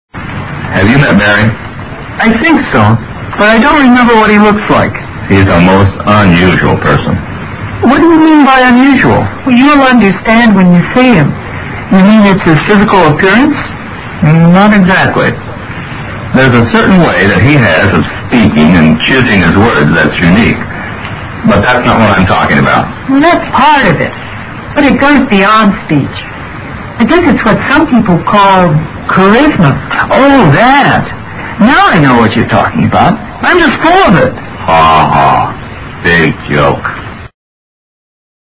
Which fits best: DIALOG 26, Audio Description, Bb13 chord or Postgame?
DIALOG 26